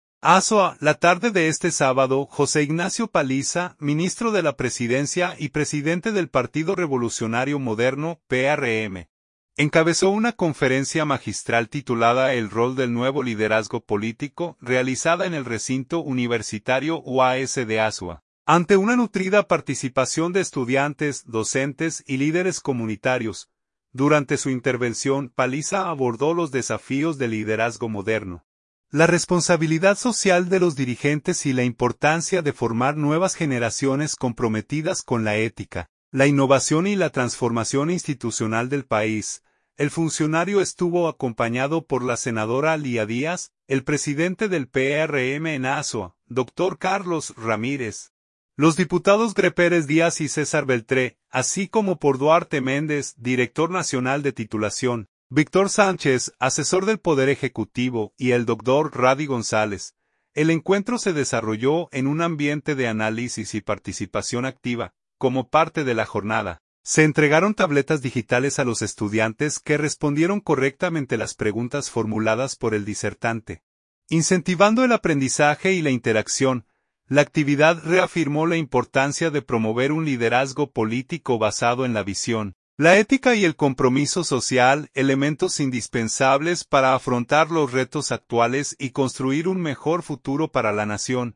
Azua.– La tarde de este sábado, José Ignacio Paliza, ministro de la Presidencia y presidente del Partido Revolucionario Moderno (PRM), encabezó una conferencia magistral titulada “El Rol del Nuevo Liderazgo Político”, realizada en el recinto universitario UASD Azua, ante una nutrida participación de estudiantes, docentes y líderes comunitarios.